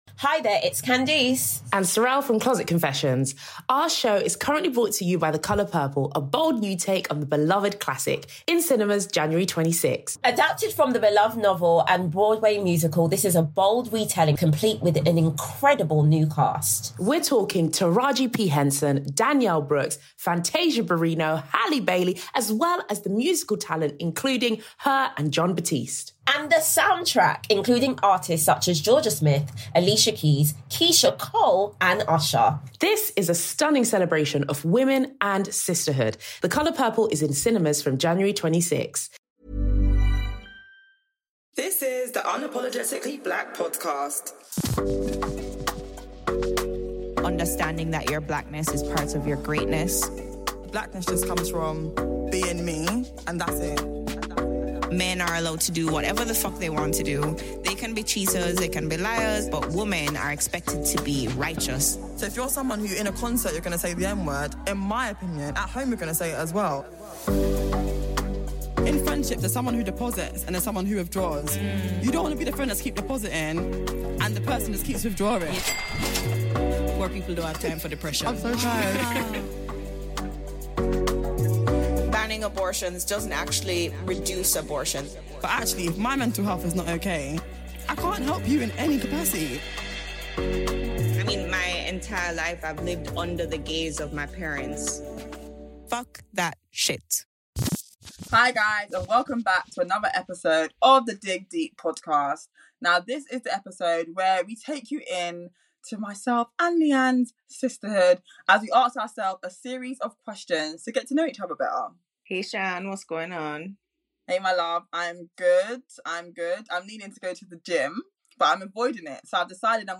We ask each other a series of tough and often intimate questions for the first time.